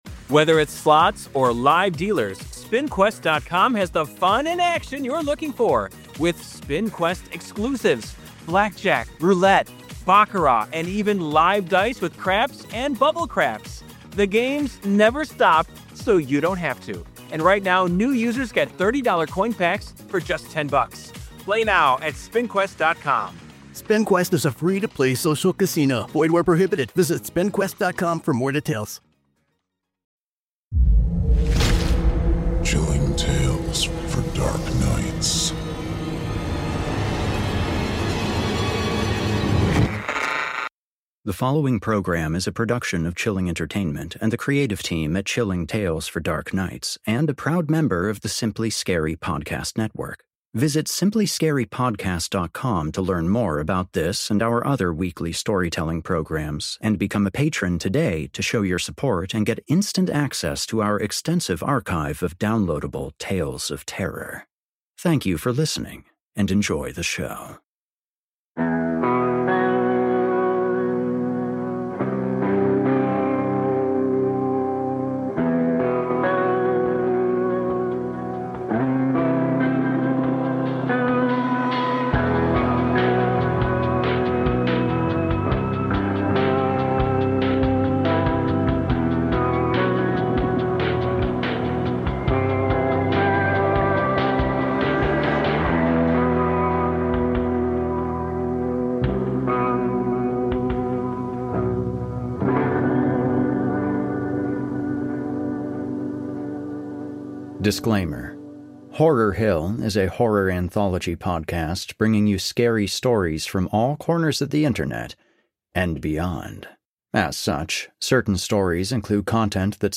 When a struggling chef with dreams of greatness crosses paths with a seasoned mushroom hunter guarding his best-kept secrets, an unexpected discovery promises to change everything. Darkly atmospheric and disturbingly visceral, this episode explores temptation, ambition, and the terrifying cost of indulging in something you don’t fully understand.